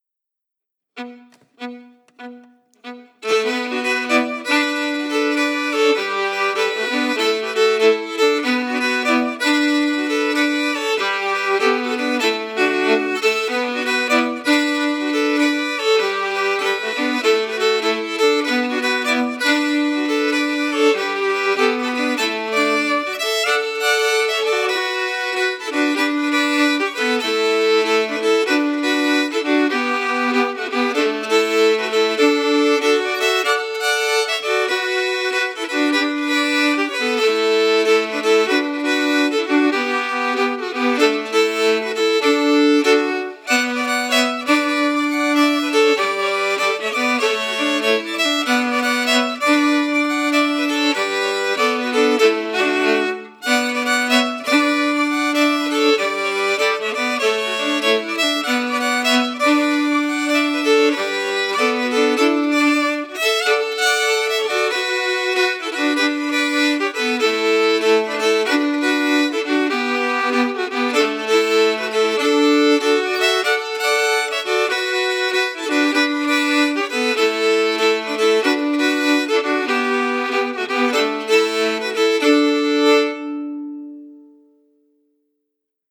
Key: Bm
Form: Jig
Harmony emphasis
Region: Shetland